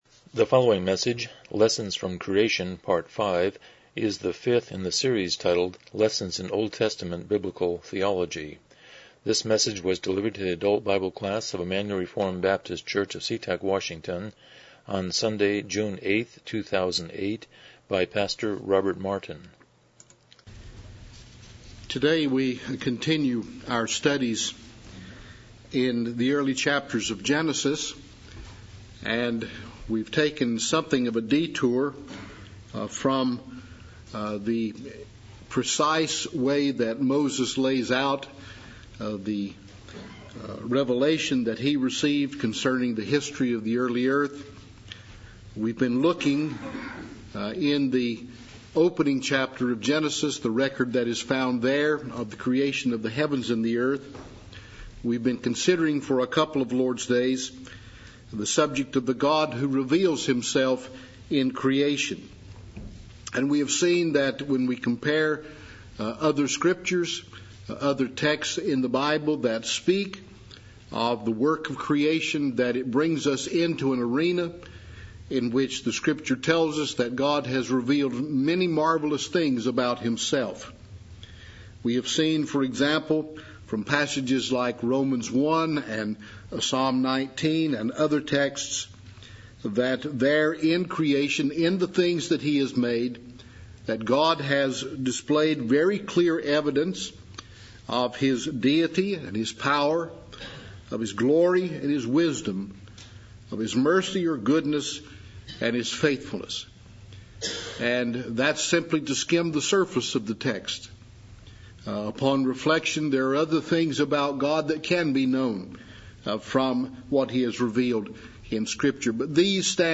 Lessons in OT Biblical Theology Service Type: Sunday School « 04 Lessons from Creation